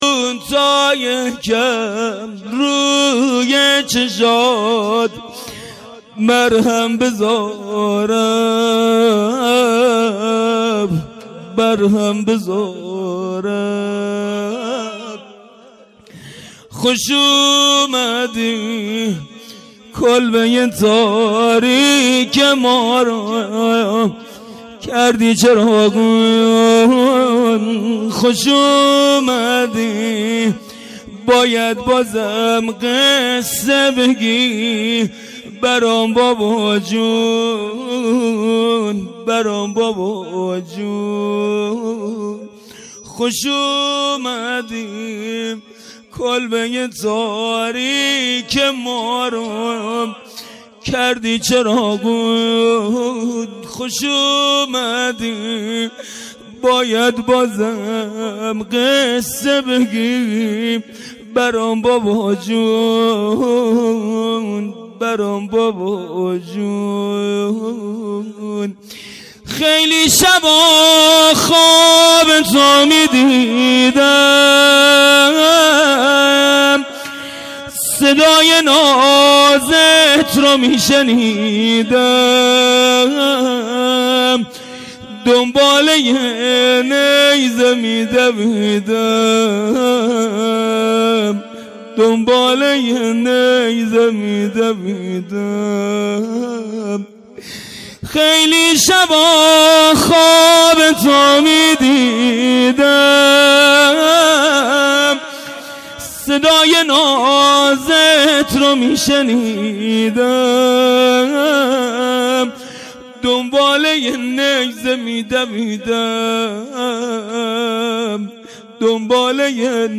خیمه گاه - هیئت ذبیح العطشان کرمانشاه - جلسه هفتگی-زمزمه 971213